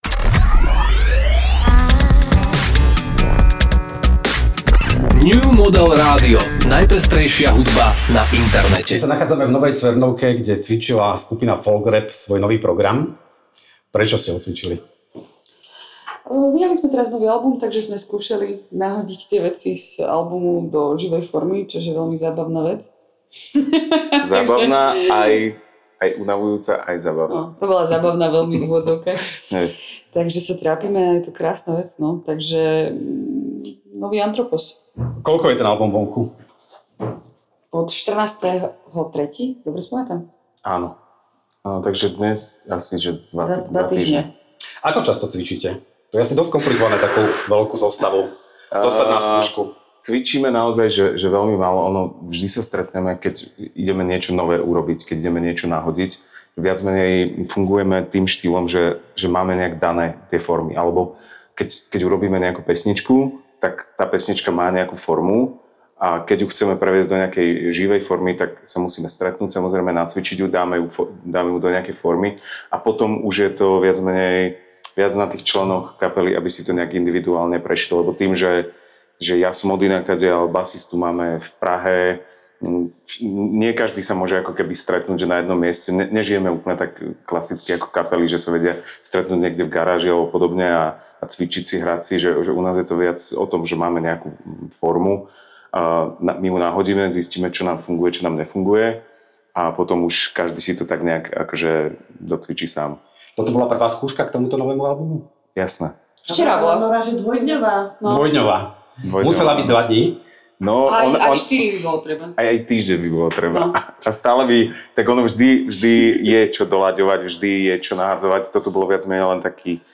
v nasledujúcom rozhovore